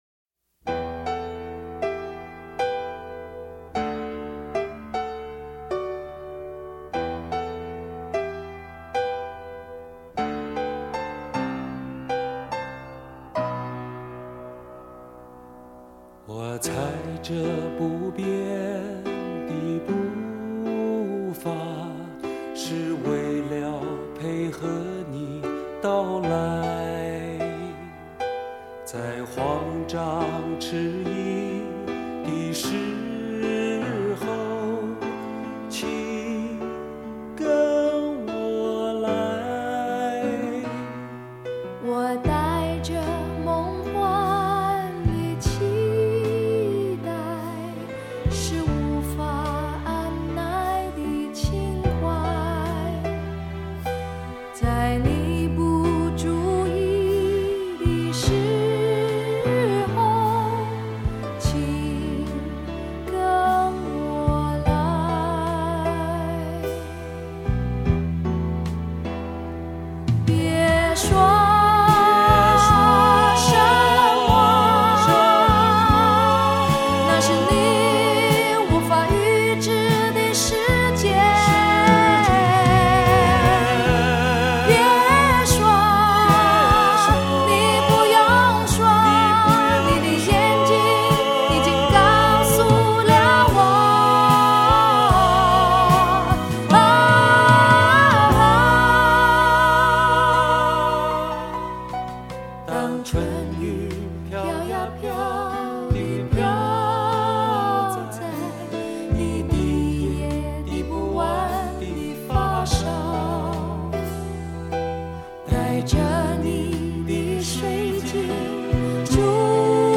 沧桑的声音，深沉的感情！